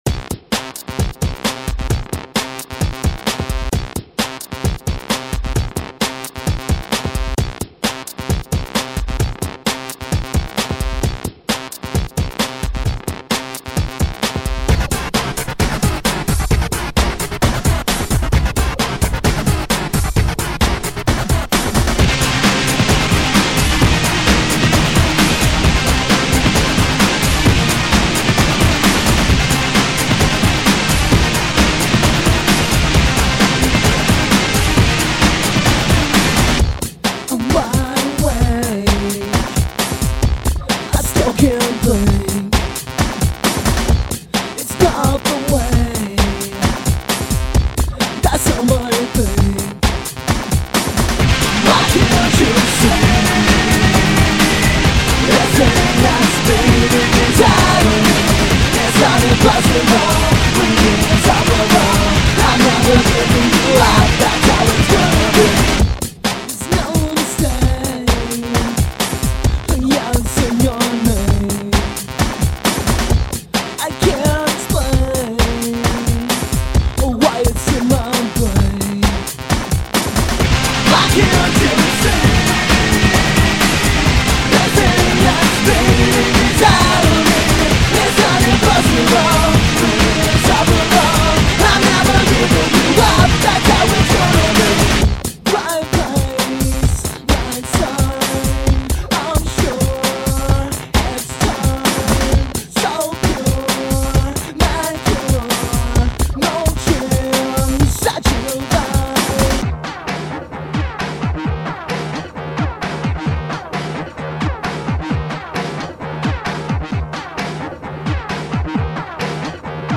dance/electronic
Breaks & beats
Electro
Pop